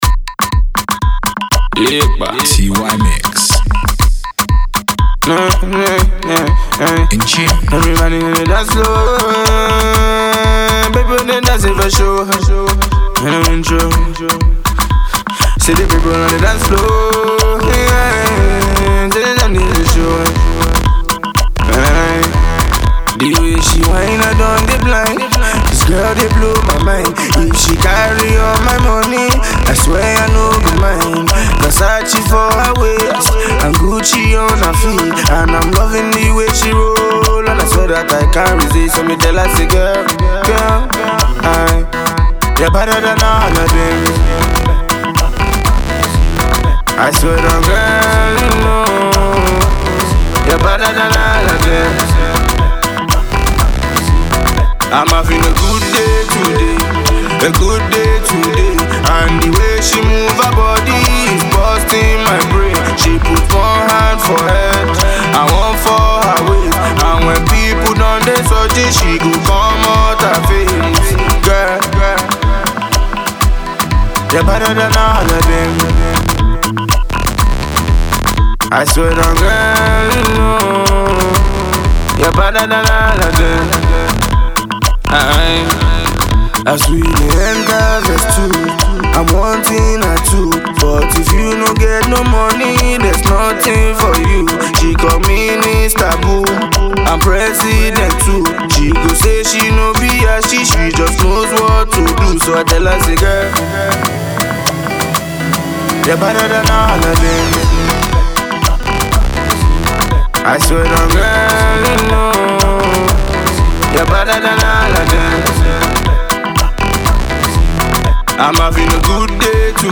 Afro Fusion
South African House, Azonto